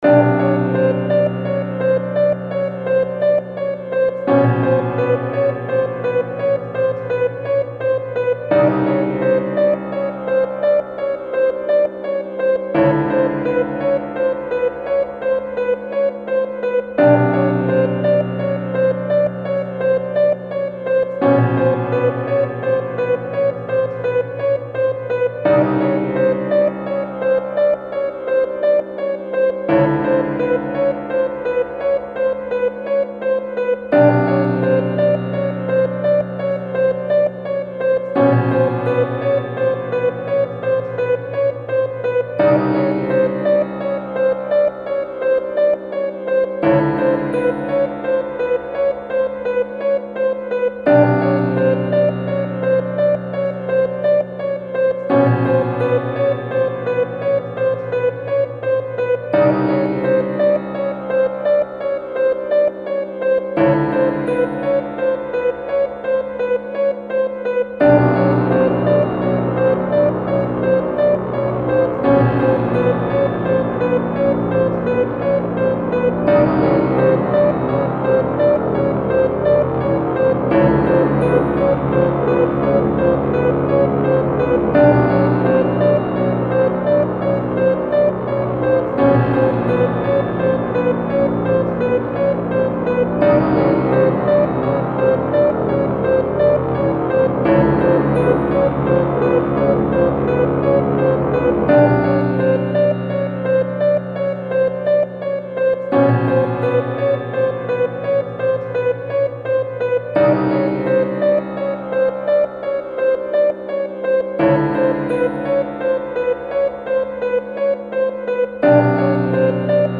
old school style of horror music